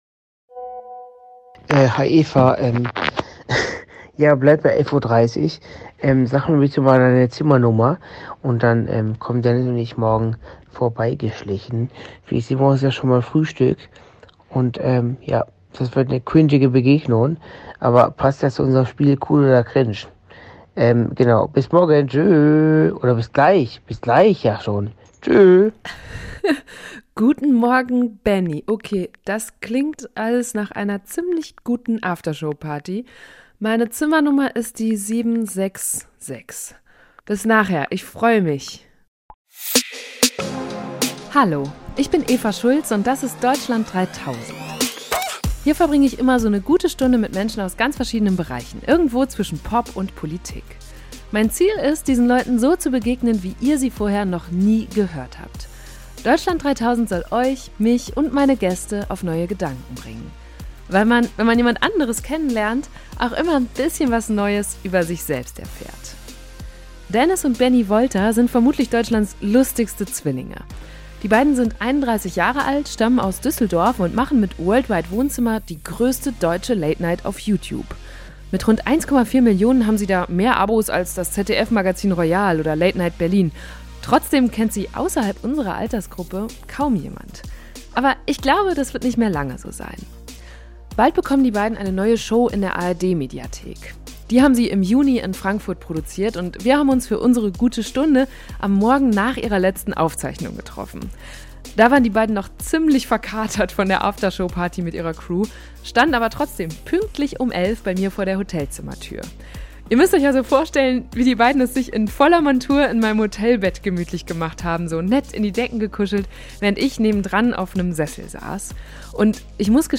Da waren die beiden noch ziemlich verkatert von der After-Show-Party mit ihrer Crew, standen aber trotzdem pünktlich um elf bei mir vor der Hotelzimmertür. In dieser Folge haben sie mir erzählt, was einen guten Anzug ausmacht und warum sie beide bis heute keinen Führerschein haben.